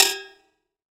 6TIMBALE H1P.wav